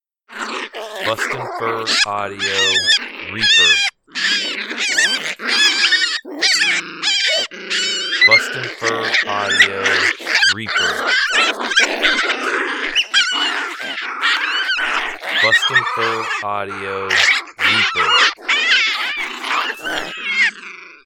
Coyote Pup vs Juvenile Groundhog fight. Excellent change up sound that produces.